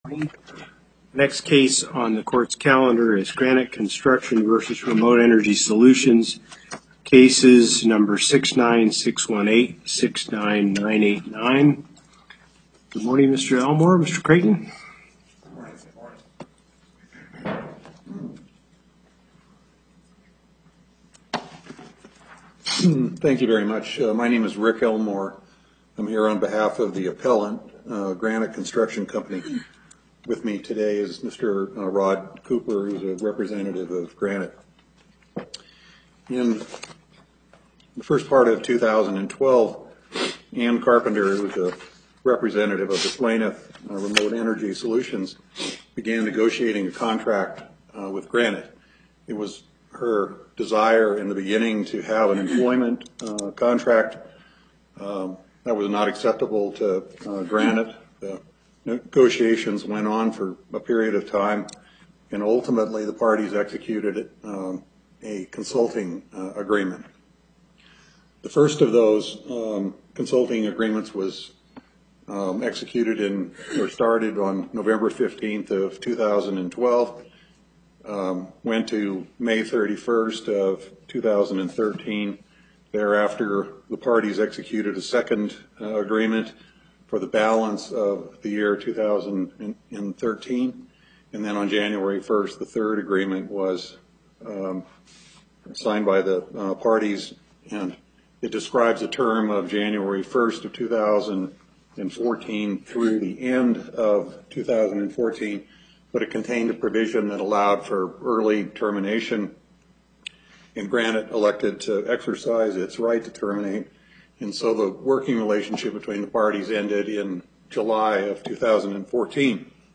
Location: Carson City Before the Northern Nevada Panel - Justice Hardesty, Presiding